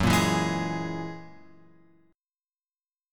F#9 chord {2 1 x 1 2 0} chord